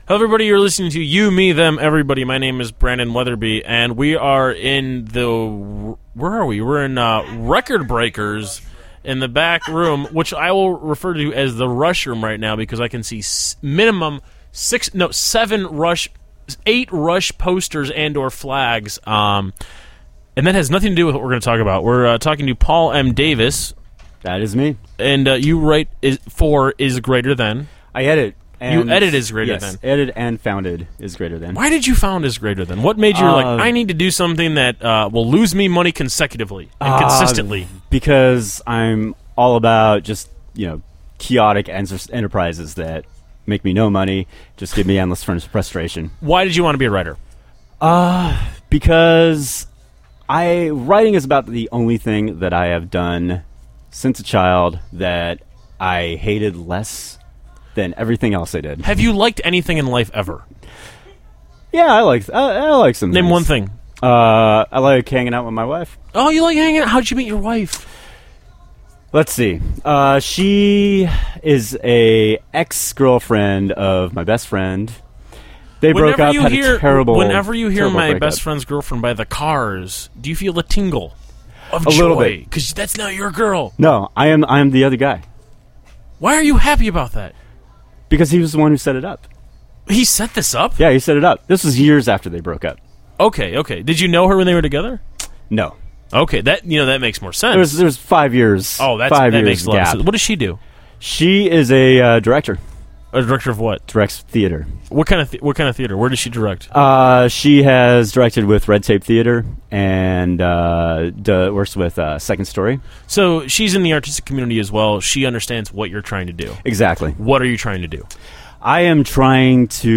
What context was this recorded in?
at the Printers Ball After Party in Record Breakers - You, Me, Them, Everybody